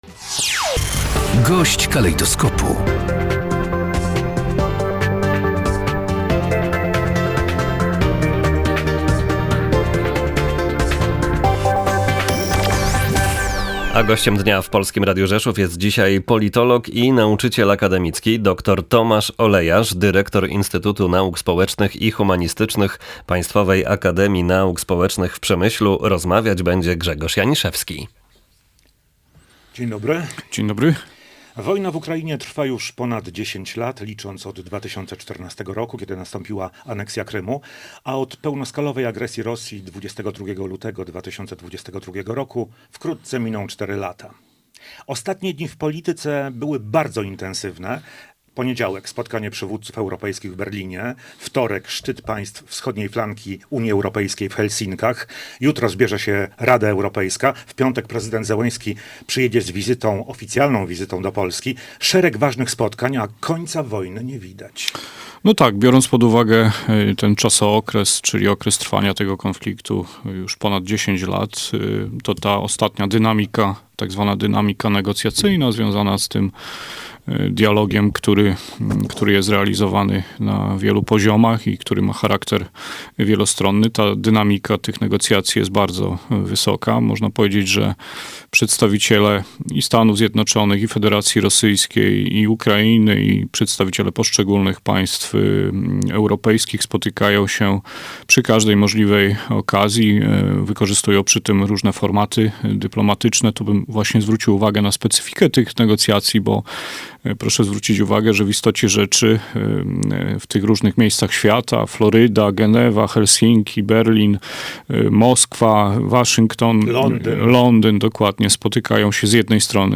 Gość dnia • Przemyski politolog